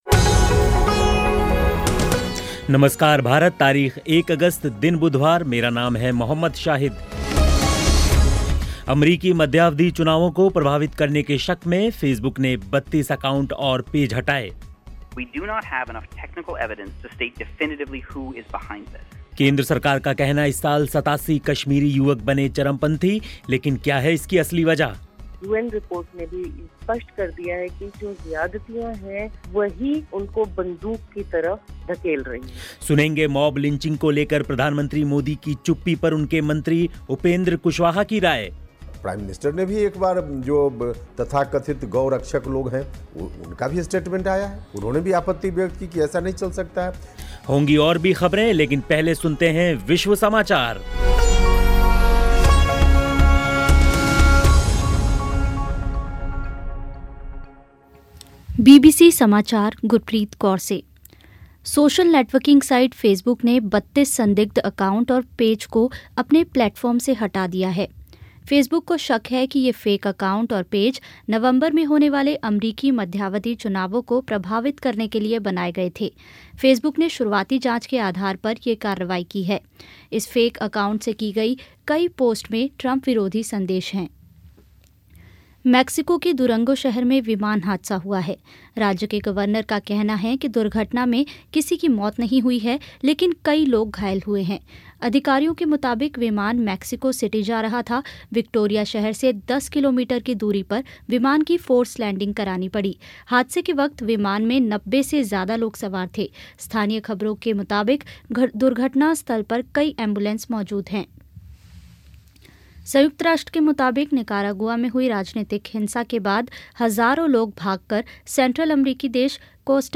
मॉब लिंचिंग को लेकर प्रधानमंत्री मोदी की चुप्पी पर उनके मंत्री उपेंद्र कुशवाहा की राय और पूरा इंटरव्यू.